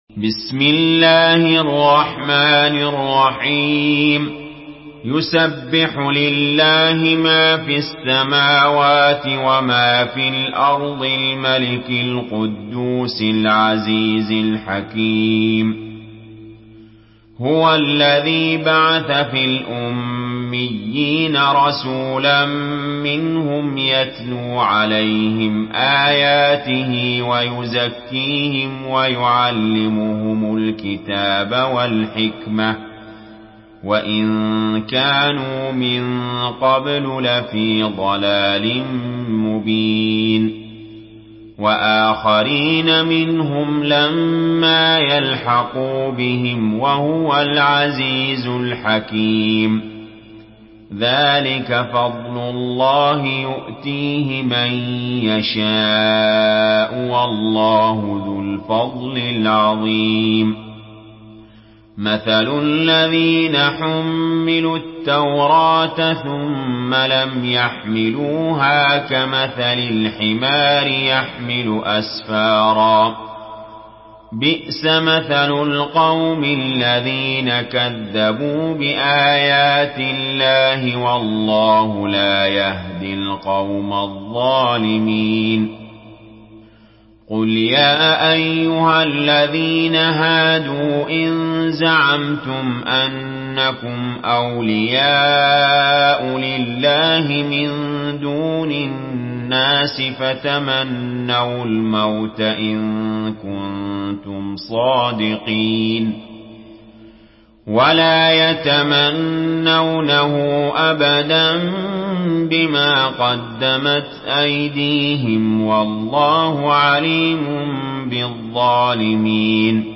Surah الجمعه MP3 by علي جابر in حفص عن عاصم narration.